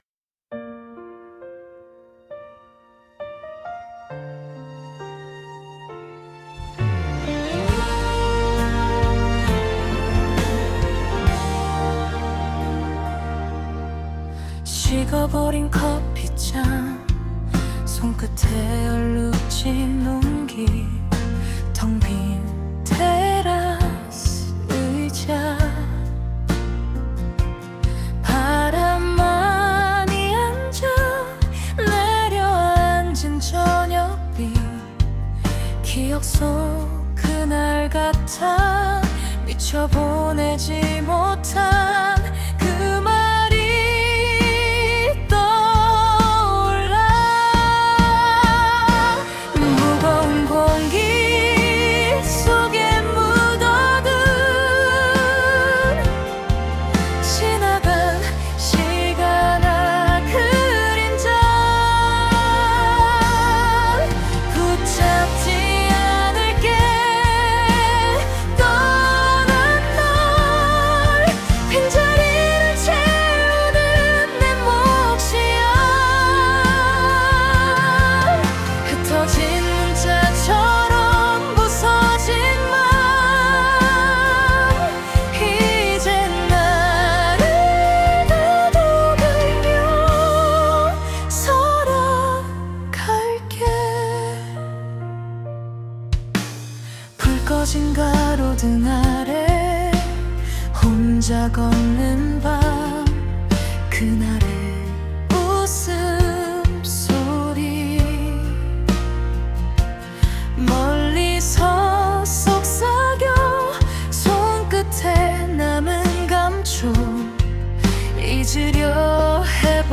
다운로드 설정 정보 Scene (장면) cafe_terrace Topic (주제) 식어가는 커피, 늦은 후회, 빈자리, 미처 보내지 못한 문자. 떠난 사람을 붙잡기보다, 남겨진 나를 다독이며 하루를 정리하는 마음 Suno 생성 가이드 (참고) Style of Music Bossa Nova, Jazz Piano, Lo-Fi, Female Vocals, Soft Voice Lyrics Structure [Meta] Language: Korean Topic: 식어가는 커피, 늦은 후회, 빈자리, 미처 보내지 못한 문자.